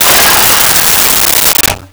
Metal Crash 02
Metal Crash 02.wav